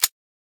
grenadepull.ogg